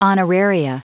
Prononciation du mot honoraria en anglais (fichier audio)
Prononciation du mot : honoraria